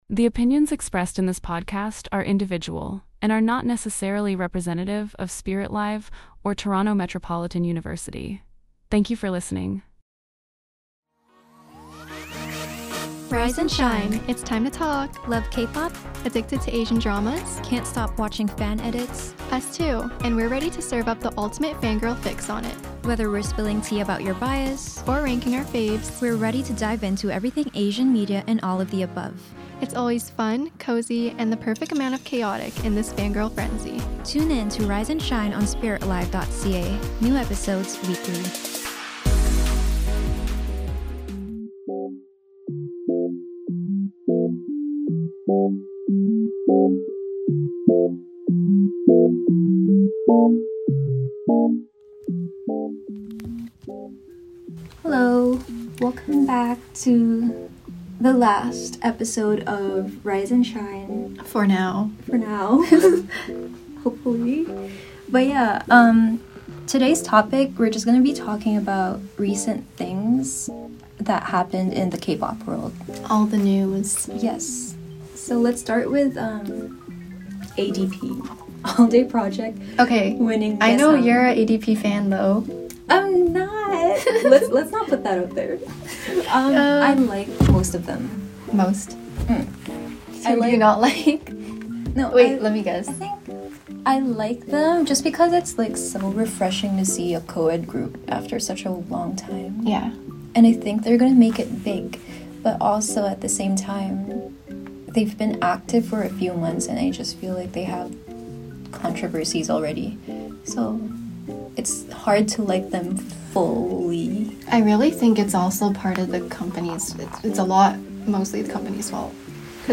cute vlog music